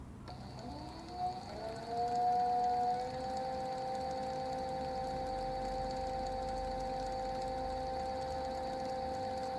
Звук старта поездки на электроскутере